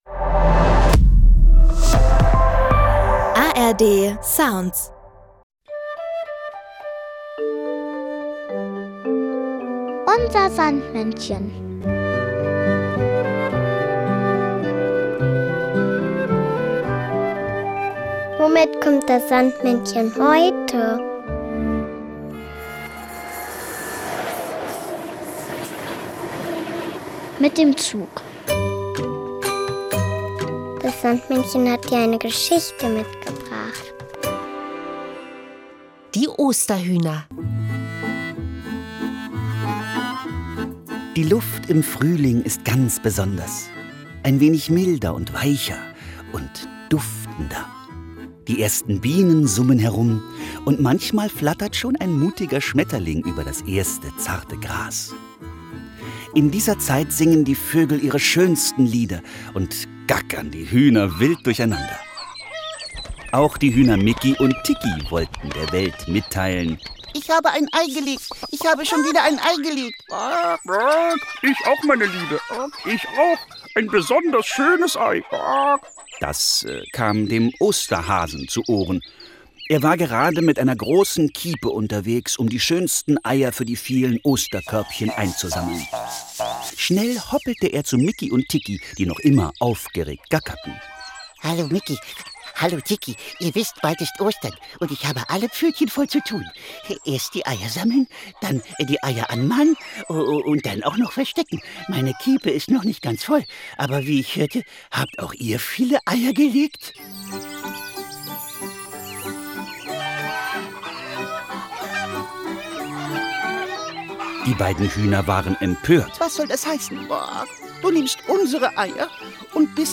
Unser Sandmännchen: Geschichten und Lieder 14 ~ Unser Sandmännchen Podcast